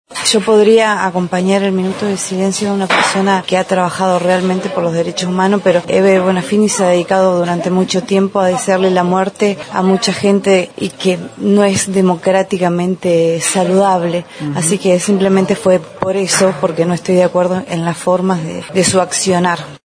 Luego de finalizada la sesión, consultamos a la Concejal Otero sobre esa situación y dijo lo siguiente.
17°-sesion-hcd-1-karina-otero-hebe-de-bonafini.mp3